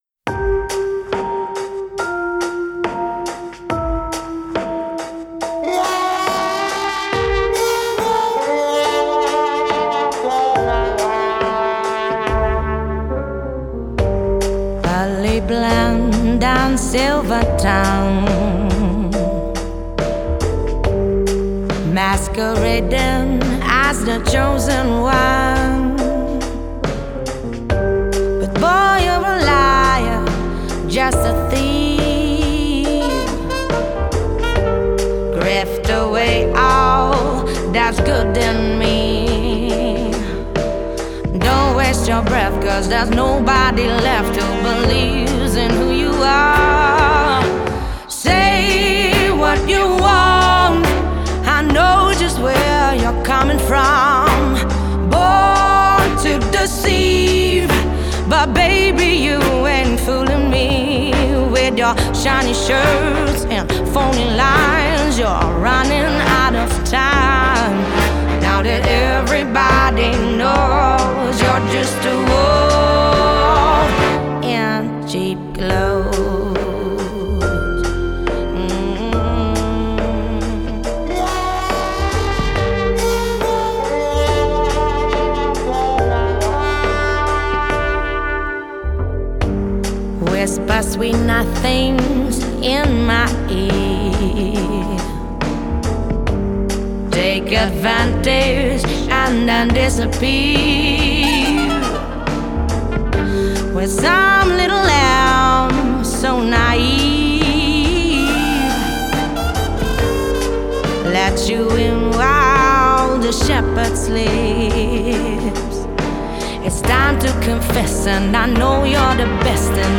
Genre: Indie Pop, Soul, Jazz